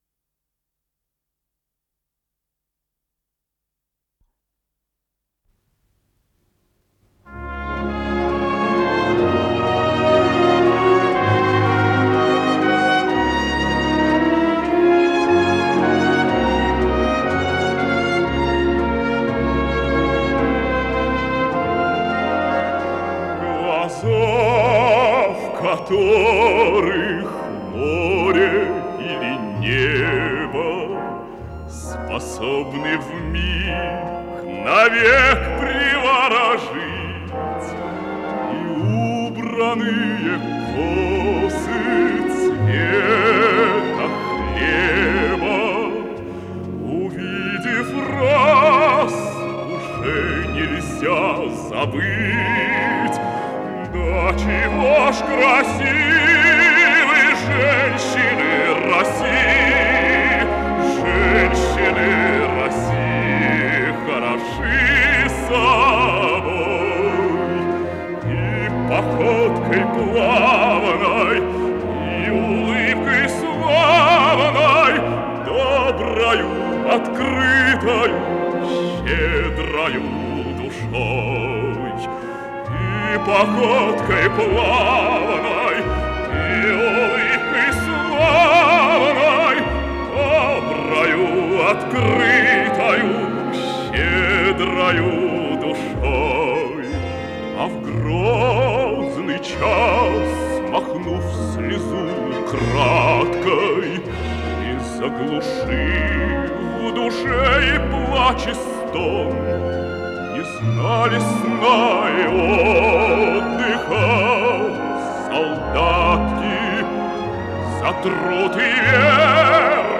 с профессиональной магнитной ленты
пение
ВариантДубль моно